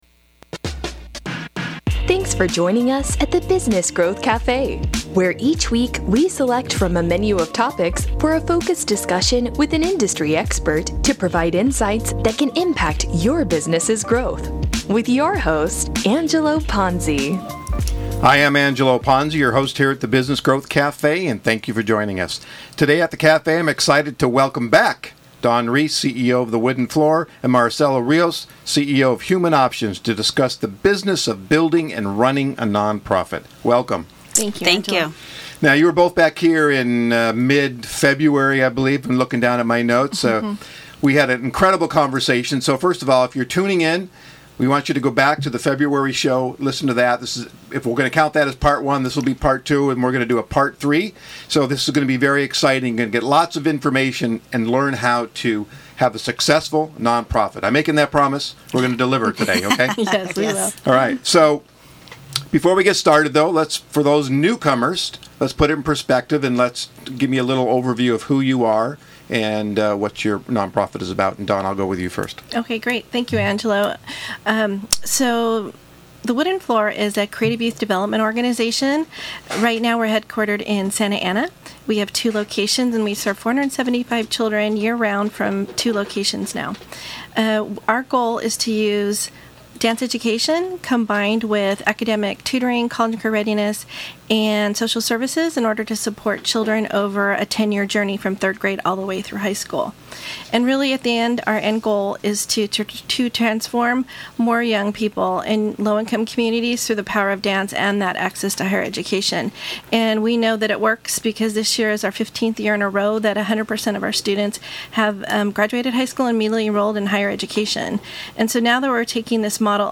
Human Options in our discussion at the Business Growth Café.